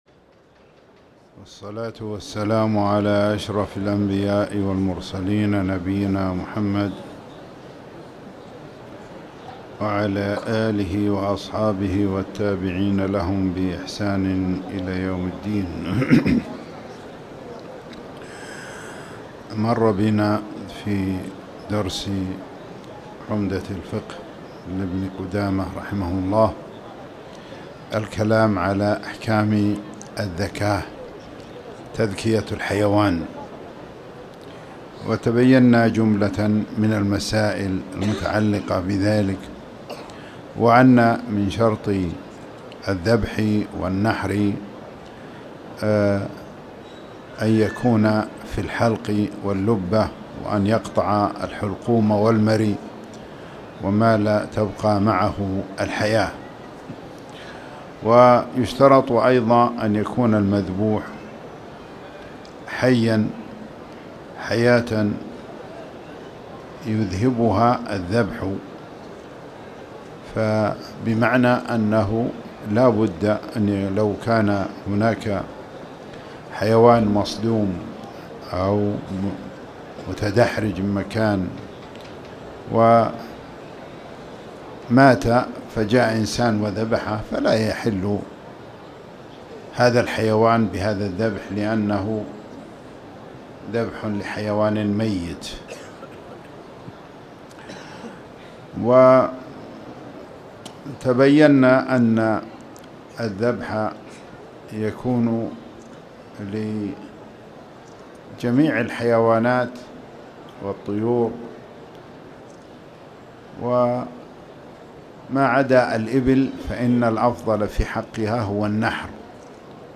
تاريخ النشر ٢١ شعبان ١٤٣٨ هـ المكان: المسجد الحرام الشيخ